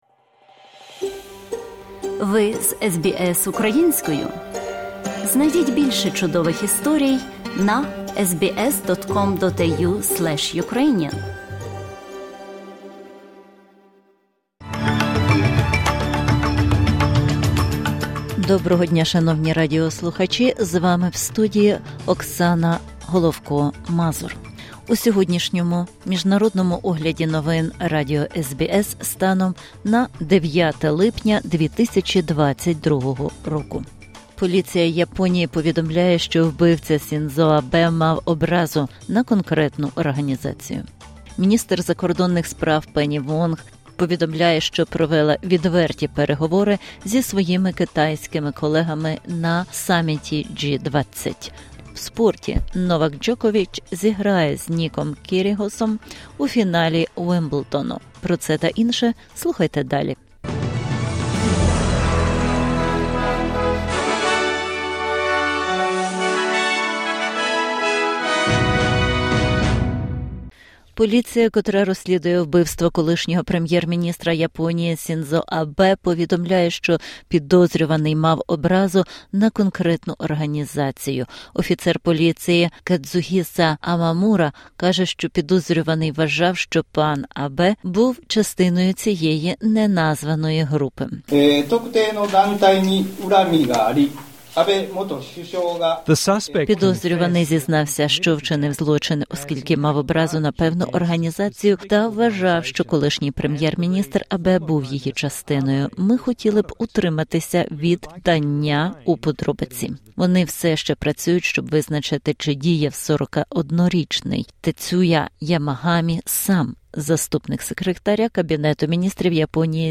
SBS News in Ukrainian - 9/07/2022